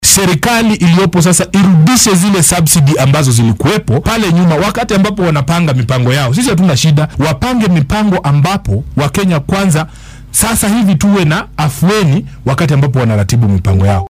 Edwin Sifuna oo wareysi gaar ah siinayay idaacadda Radio Citizen ayaa sheegay in mucaaradka ay hanjabaadooda ka dhabeyn doonaan haddii dowladdu aynan qaadin tallaabo degdeg ah.